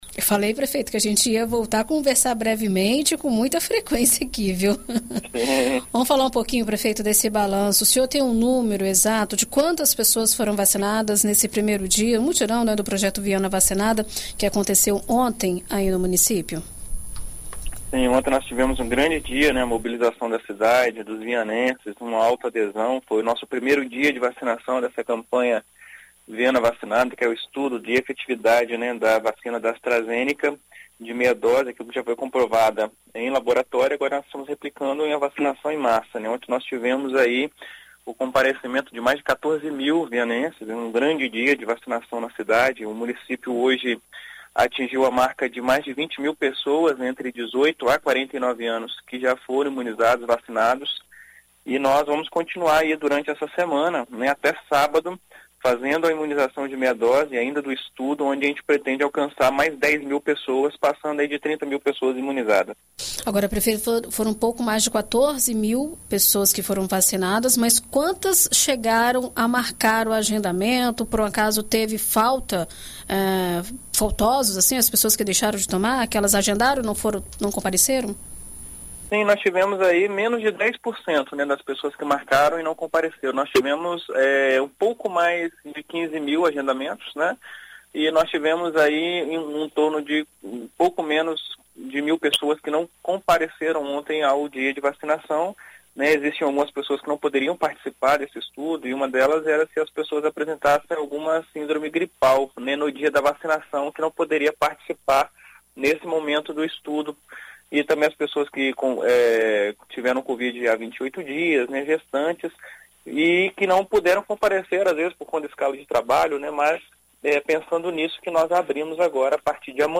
Em entrevista à BandNews FM Espírito Santo nesta segunda-feira (14), o prefeito da cidade, Wanderson Bueno, detalha o planejamento do projeto e fala sobre a continuidade da vacinação.